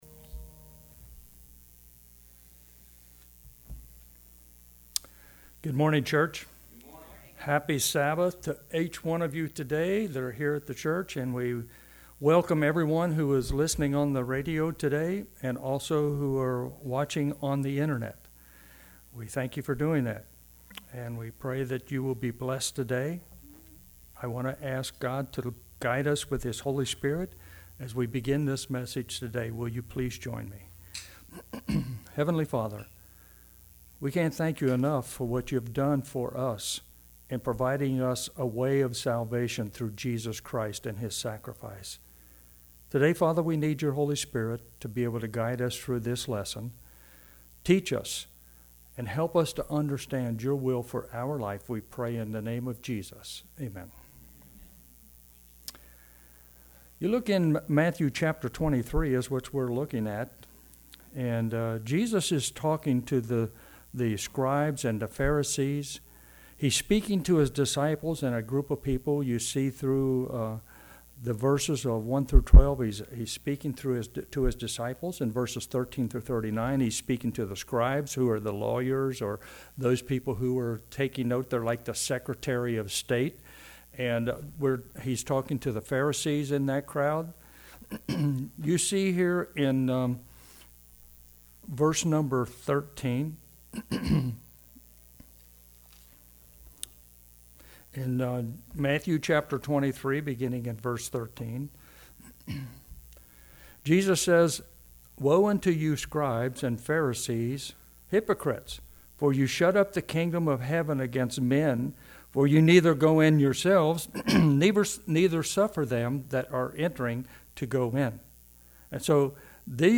Sermon based on Matthew 23:3,4,28 & 29.